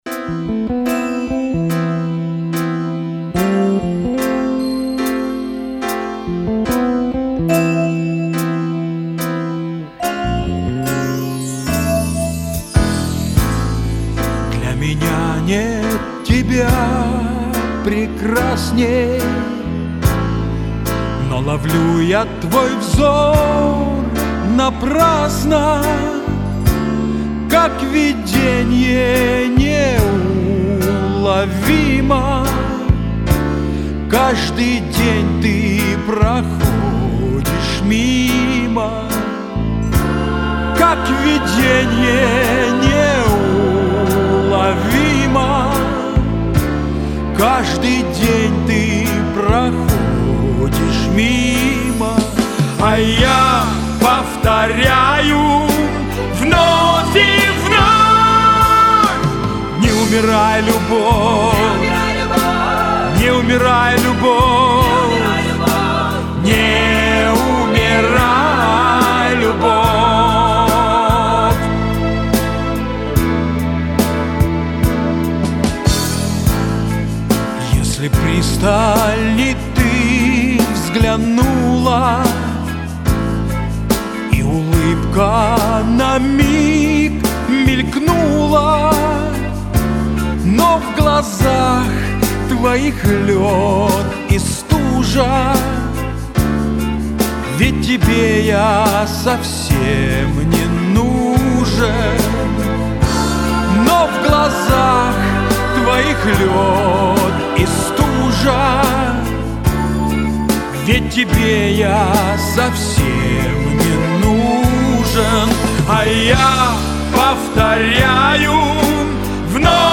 как же он поет классно ... с душой каждое слово